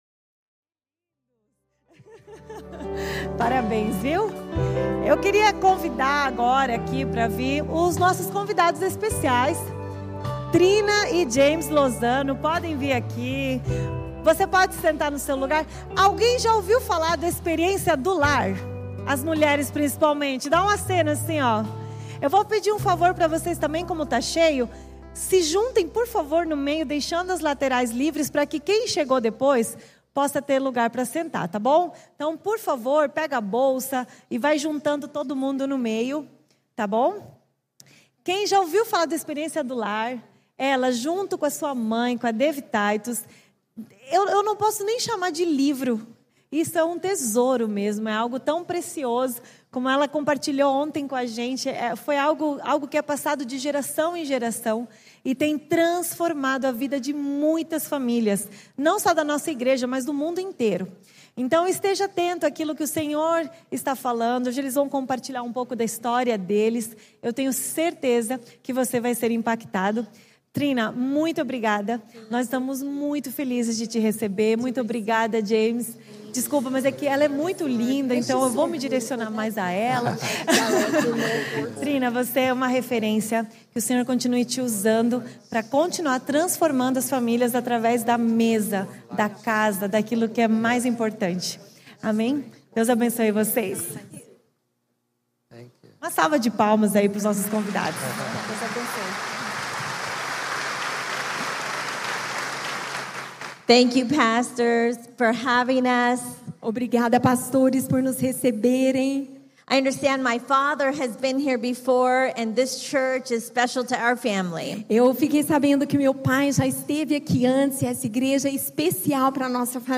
Testemunho
Primeira Igreja Batista de Curitiba